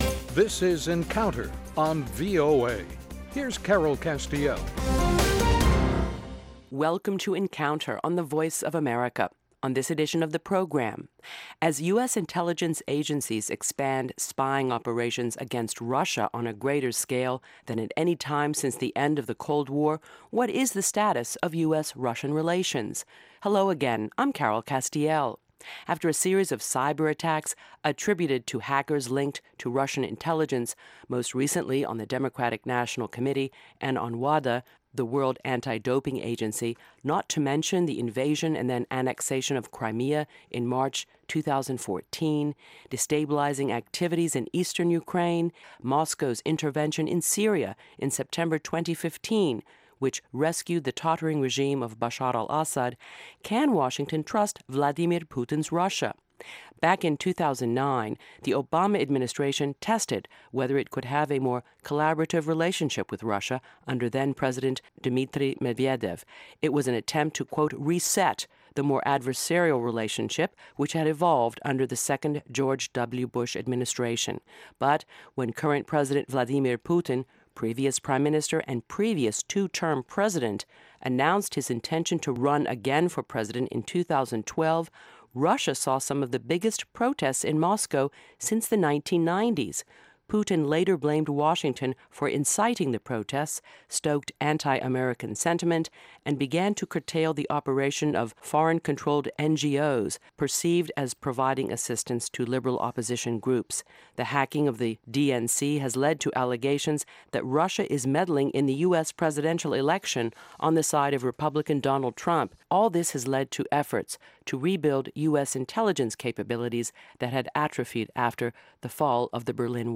Issues that affect our lives and global stability are debated in a free-wheeling, unscripted discussion of fact and opinion.